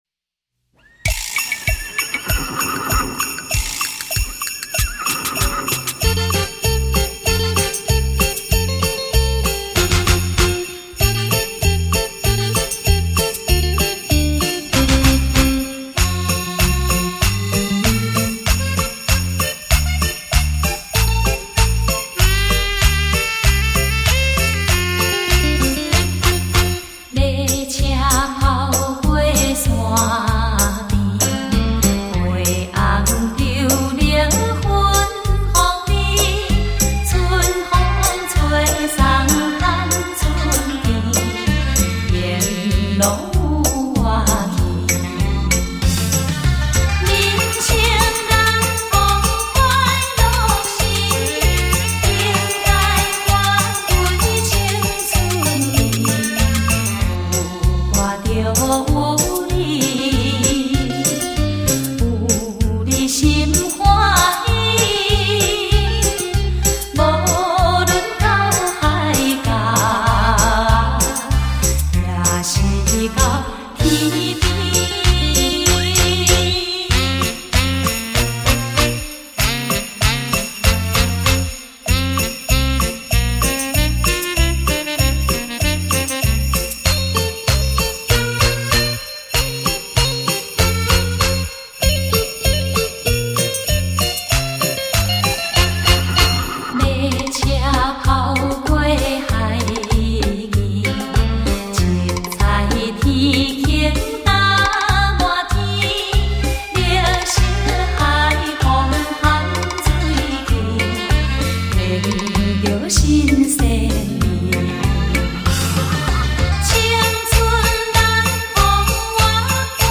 最喜欢舞曲，歌中带轻快，过瘾，谢谢分享。
环绕音效听起来就是爽 感谢楼主分享~~